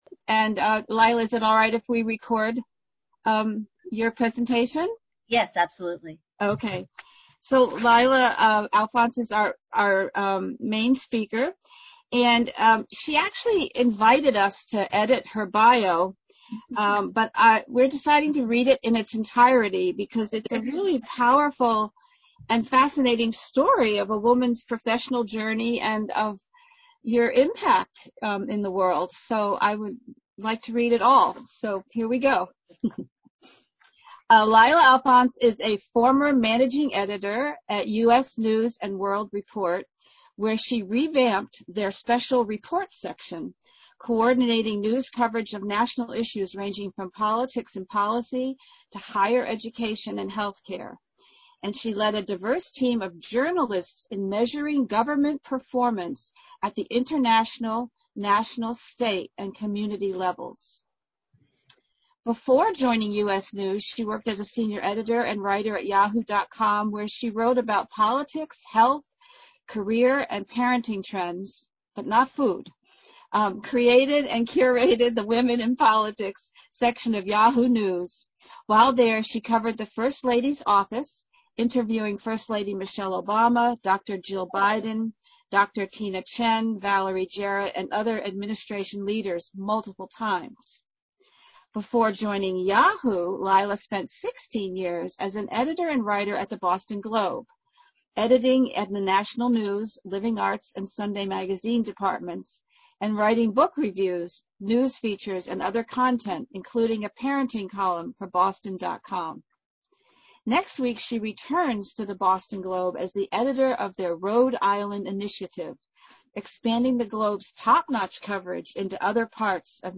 Main lecture and discussion: